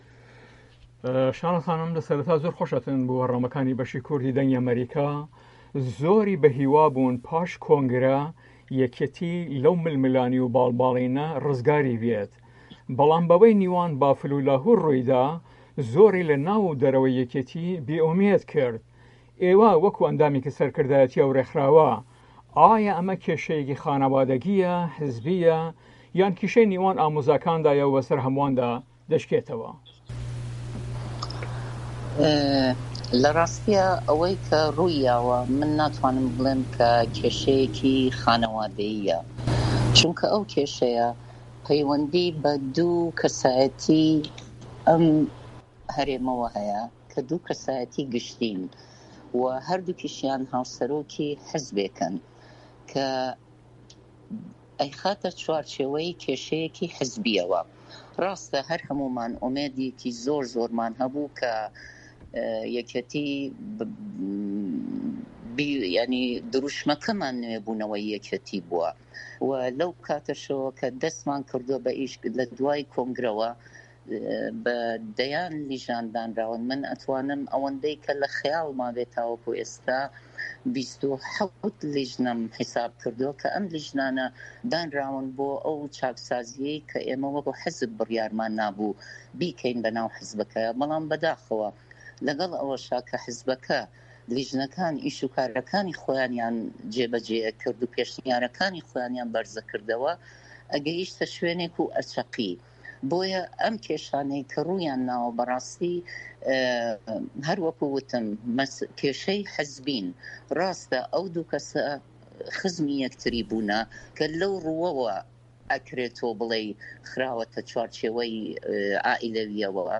دەقی وتووێژ لەگەڵ شاناز ئیبراهیم ئەحمەد